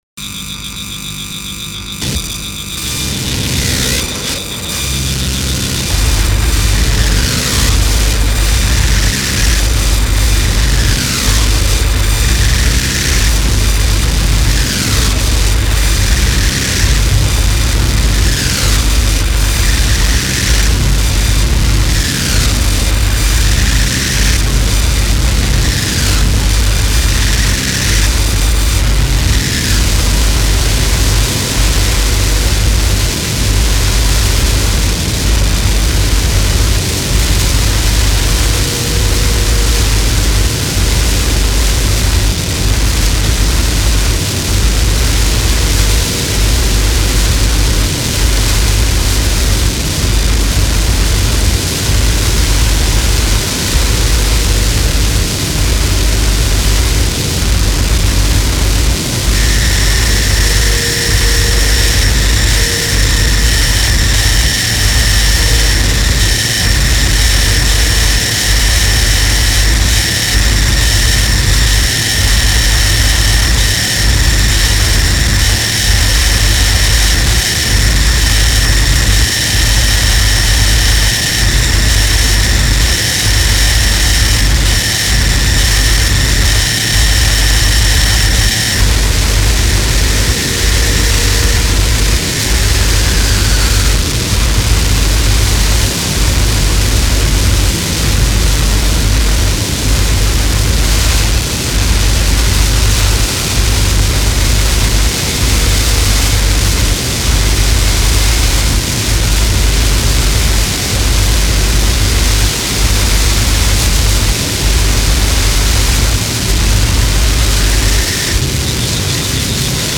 /mus/ - NOISE
Я бы добавил голос, под эффектами, хотя мне нравится и так.